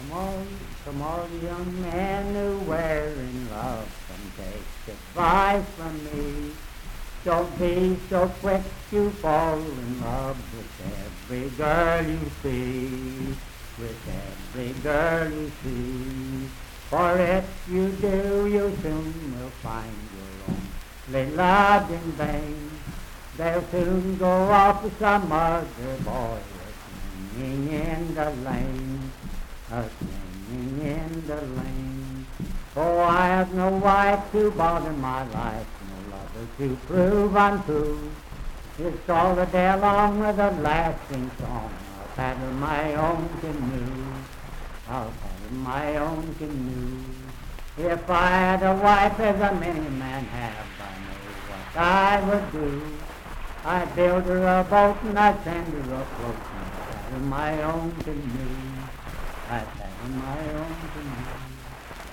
Unaccompanied vocal music
Voice (sung)
Parkersburg (W. Va.), Wood County (W. Va.)